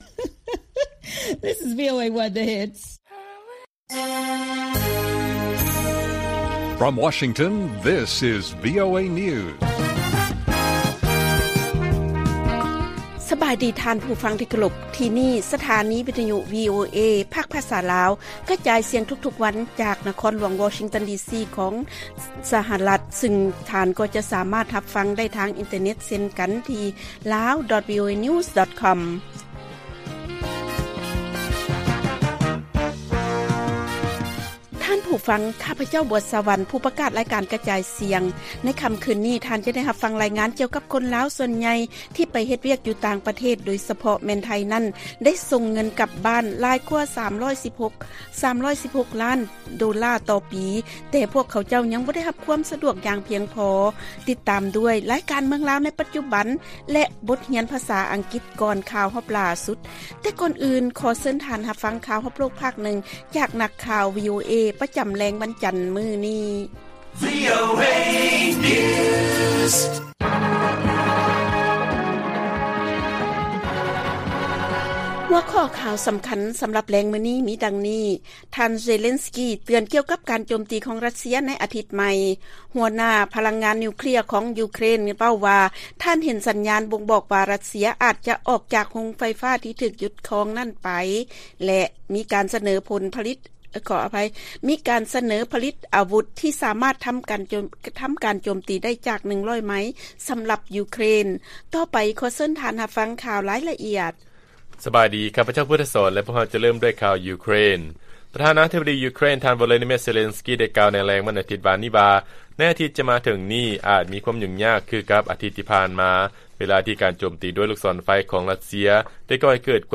ລາຍການກະຈາຍສຽງຂອງວີໂອເອ ລາວ: ທ່ານ ເຊເລັນສກີ ເຕືອນ ກ່ຽວກັບ ການໂຈມຕີຂອງ ຣັດເຊຍ ໃນອາທິດໃໝ່